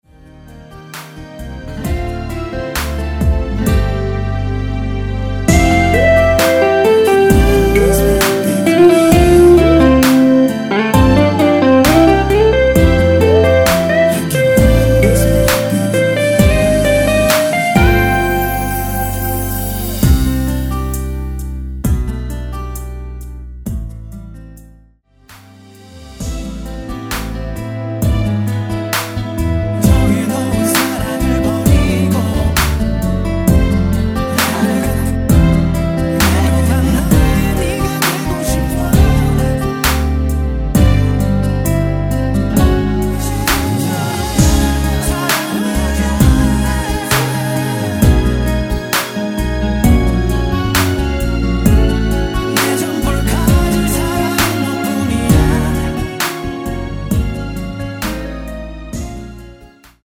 원키 코러스 포함된 MR 입니다.(미리듣기 참조)
앞부분30초, 뒷부분30초씩 편집해서 올려 드리고 있습니다.